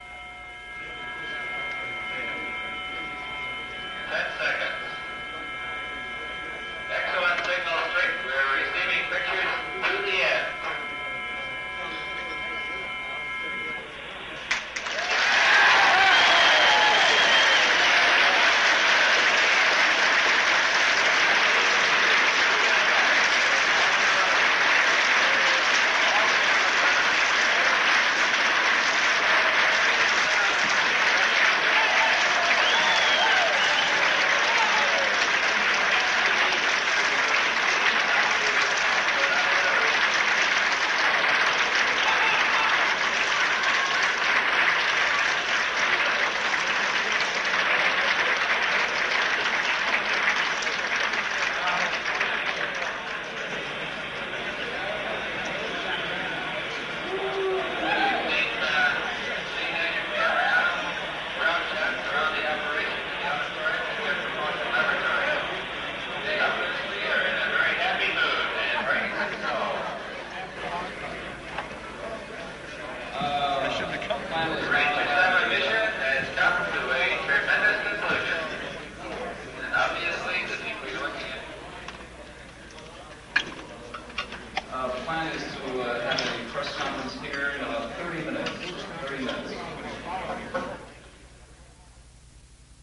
Audio recording of commentary of the lunar impact
Ranger_VII_lunar_impact.mp3